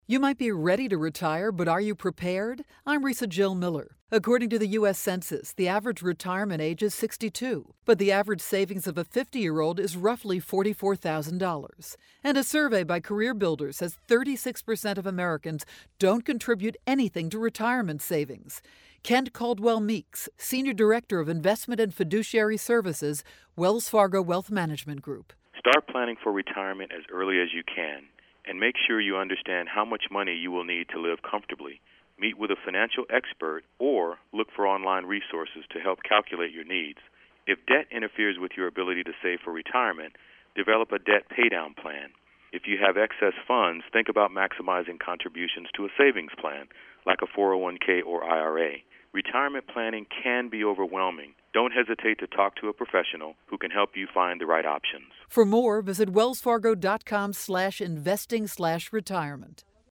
April 16, 2012Posted in: Audio News Release